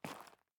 02_室外_2.ogg